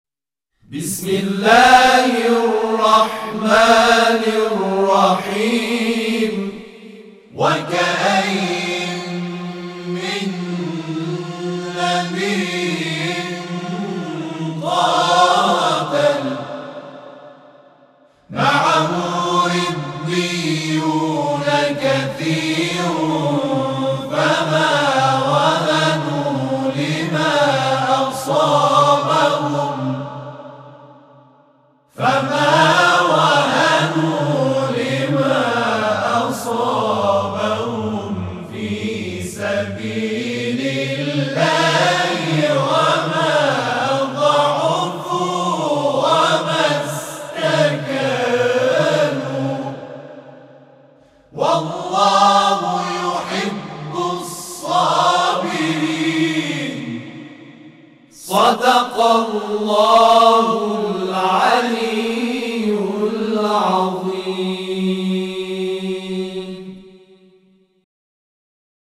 اعضای گروه مدیحه‌سرایی و هم‌خوانی محمد رسول‌الله(ص) آیه ۱۴۶ سوره مبارکه آل عمران را جمع‌خوانی کردند.
صوت جمع خوانی آیه ۱۴۶ سوره آل‌عمران